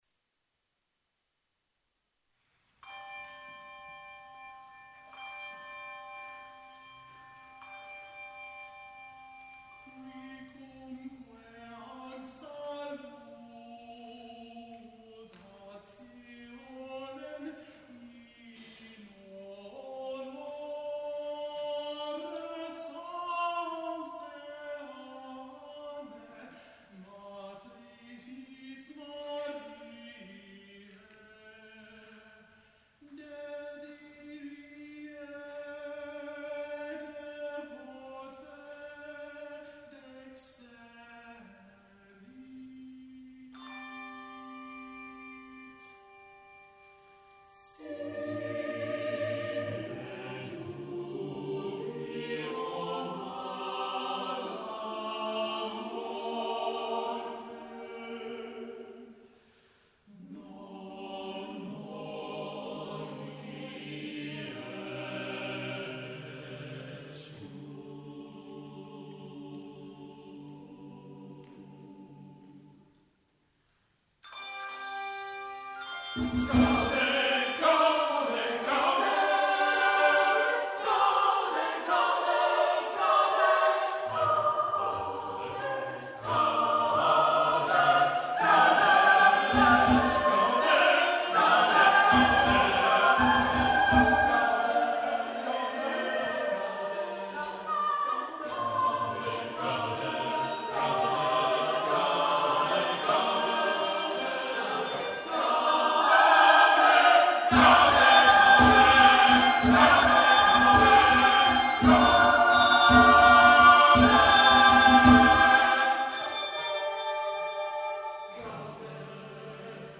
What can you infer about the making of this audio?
at the Rochester Early Music Festival